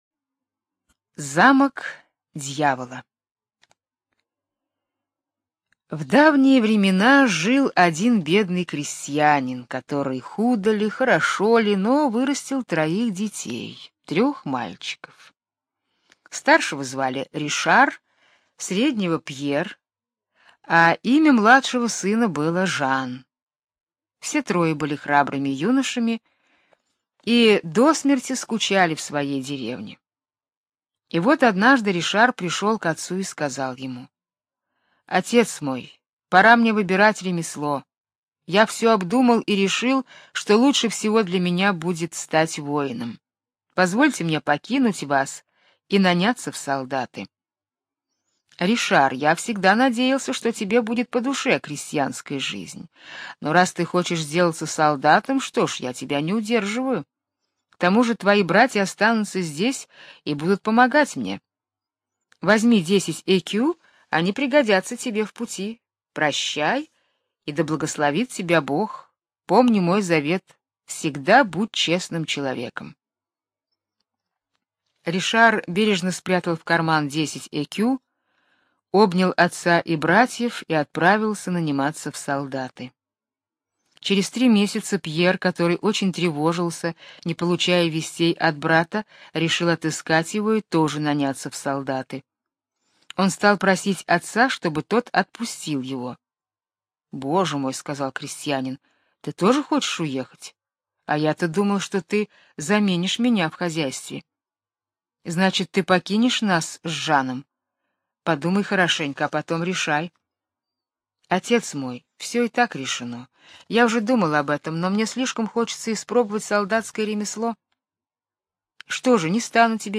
Замок дьявола - французская аудиосказка - слушать онлайн